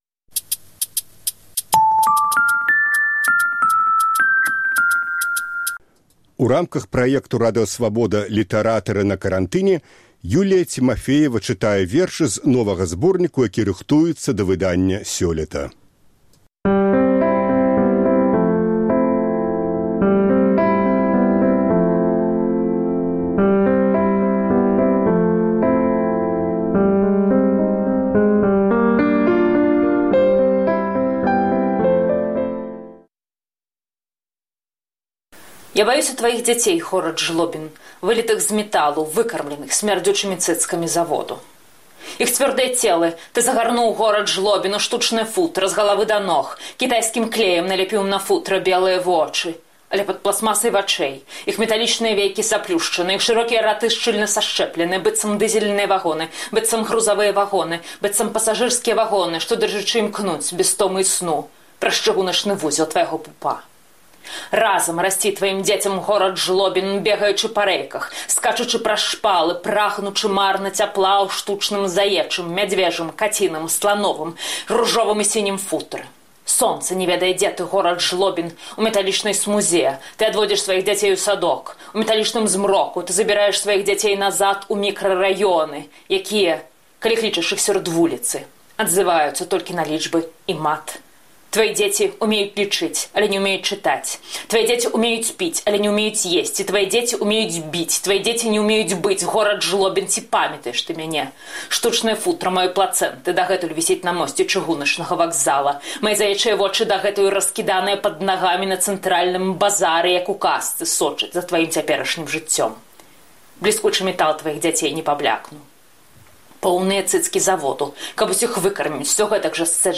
чытае вершы з новага зборніку, які рыхтуецца да выданьня сёлета
чытае сваё апавяданьне “Маскарад”